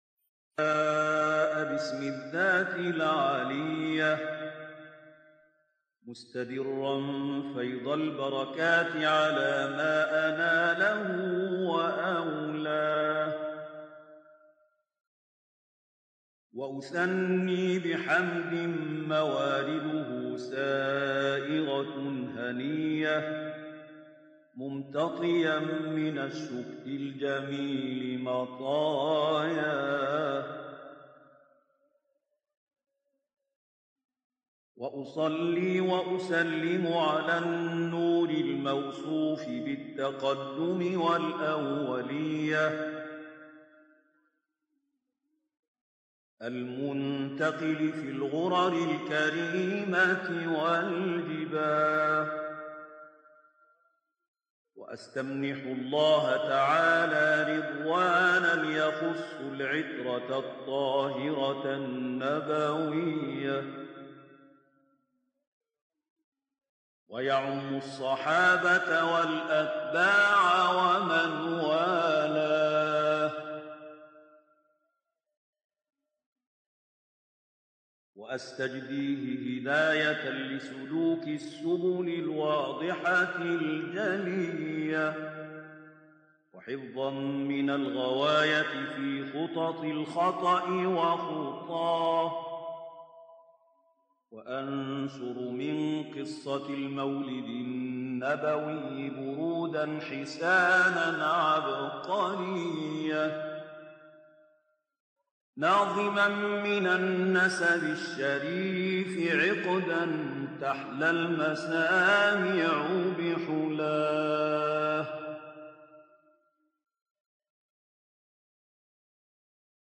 صوت | ابتها‌ل‌های ماندگار در مدح حضرت رسول (ص)
به مناسبت مبعث حضرت رسول اکرم (ص) خبرگزاری ایکنا منتخبی از ابتهال‌خوانی مبتهلان بنام جهان اسلام را منتشر می‌کند. در ادامه ابتهال‌های ماندگار از مبتهلان مصری، ایرانی و اندونزیایی را که در مدح پیامبر عظیم الشأن اسلام اجرا شده است، می‌شنوید.